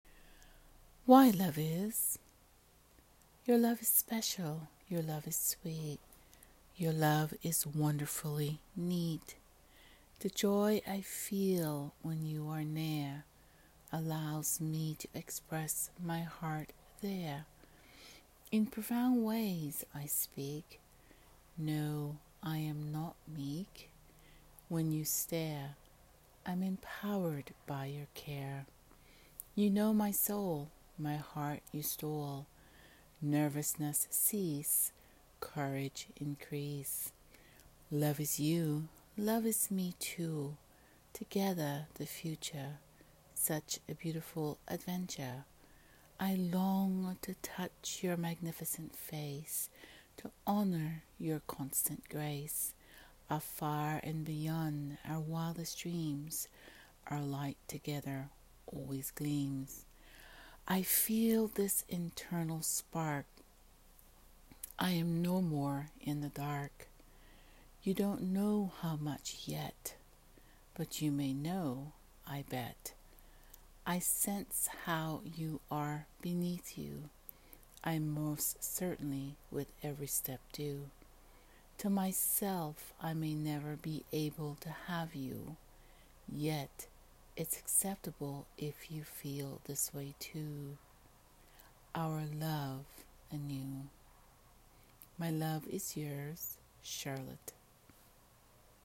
Spoken Words